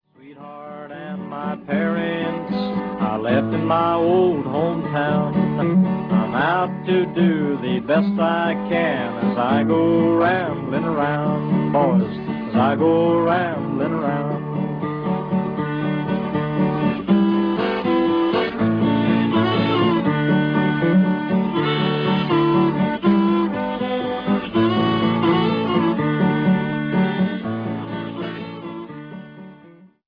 Recorded in New York, New York between 1944 and 1947.